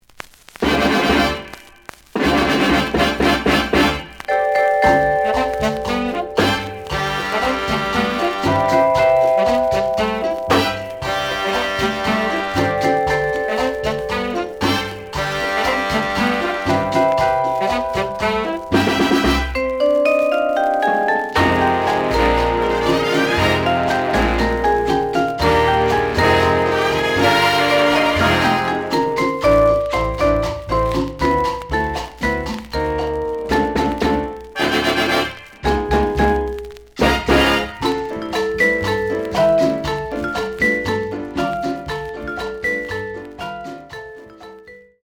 The audio sample is recorded from the actual item.
●Genre: Latin